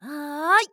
YX蓄力2.wav 0:00.00 0:00.73 YX蓄力2.wav WAV · 63 KB · 單聲道 (1ch) 下载文件 本站所有音效均采用 CC0 授权 ，可免费用于商业与个人项目，无需署名。
人声采集素材